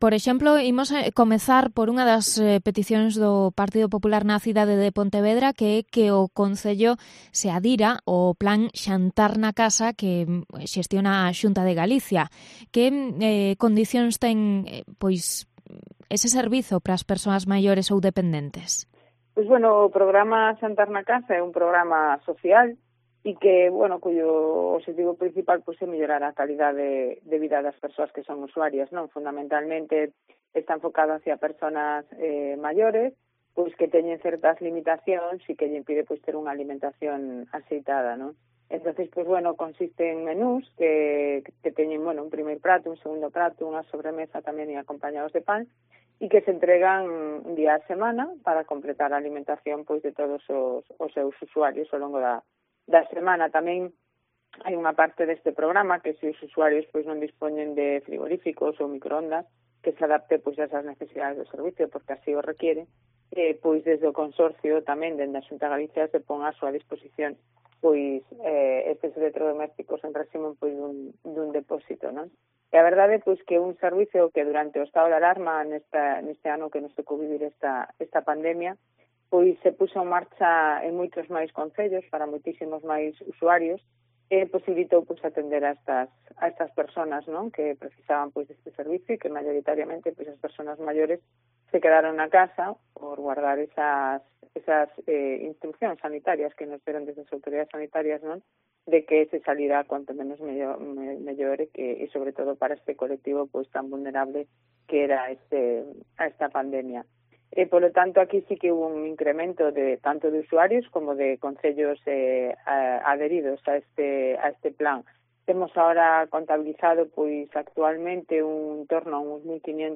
Declaraciones de la delegada territorial de la Xunta en Pontevedra sobre Xantar na Casa